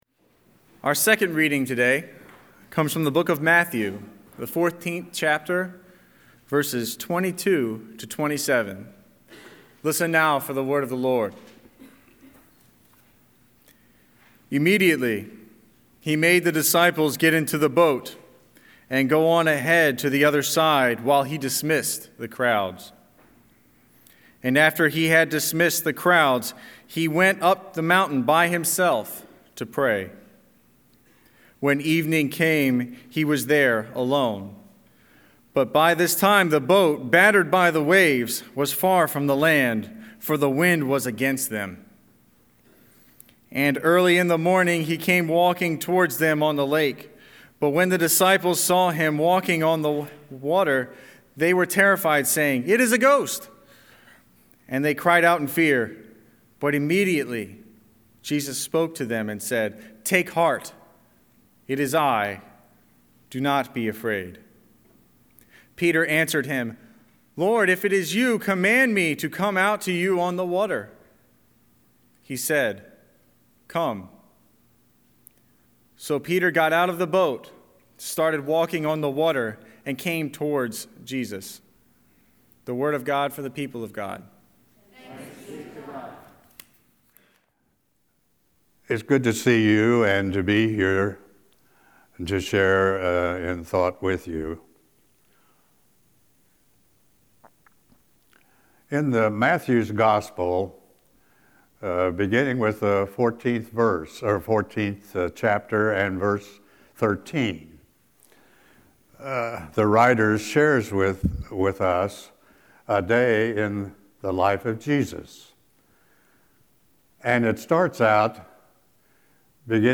Scripture and Sermon
11-8-Scripture-and-Sermon.mp3